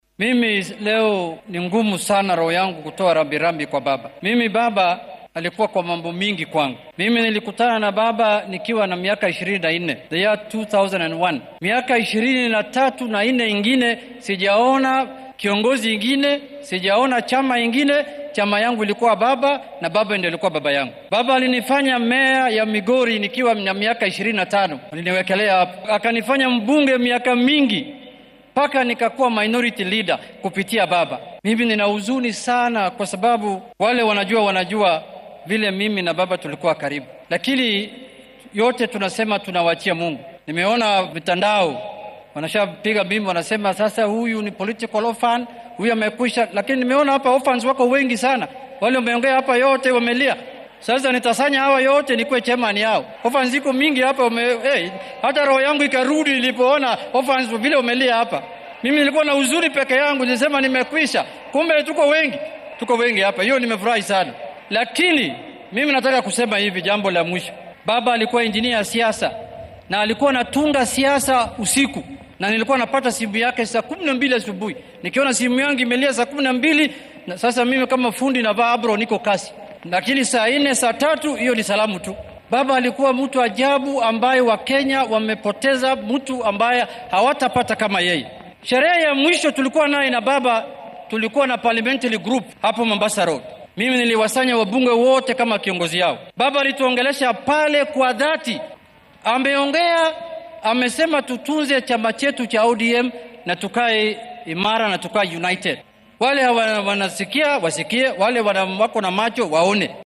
Madaxa siyaasiyiinta laga tirada badan yahay ee baarlamaanka ahna xildhibaanka deegaanka Bariga Suna ee ismaamulka Migori Junet Maxamad ayaa si kaftan ah u sheegay inuu noqon doono guddoomiyaha agoomada siyaasadeed.